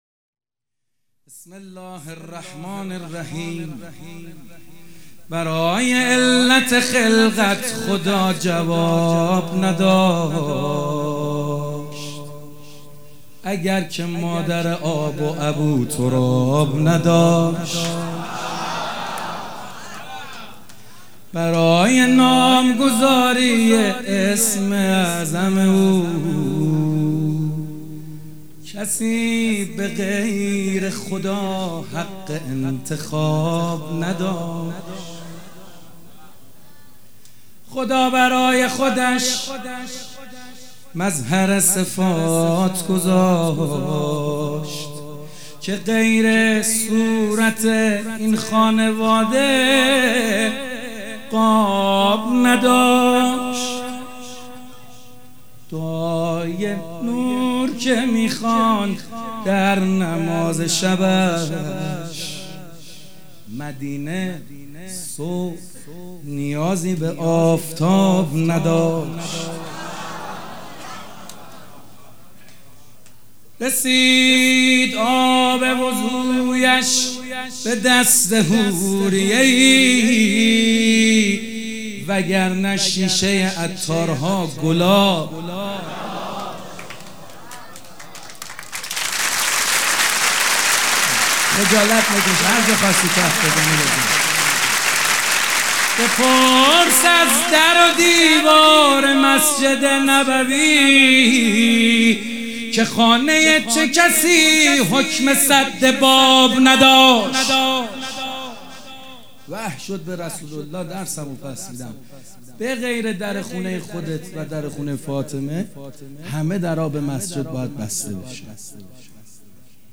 شعرخوانی
کربلایی محمدحسین حدادیان جمعه 25 بهمن 1398 حسینیه ریحانة‌الحسین (س)
سبک اثــر شعر خوانی